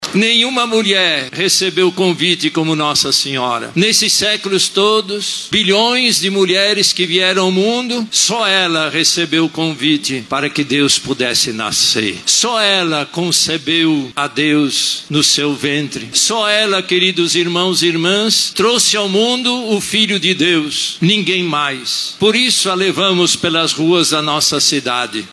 Em sua homilia, o cardeal destacou a figura fiel e humilde de Maria, que escolhida entre todas as mulheres de sua época, gerou aquele a quem nos deu a salvação, Jesus Cristo.